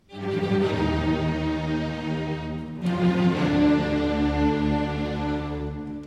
The first four notes (the Fate-motif, in German Schicksals-Motiv) and the first movement implies a narrative about something big, something menacing, like an attack on life – probably by fate.
first-four-notes.mp3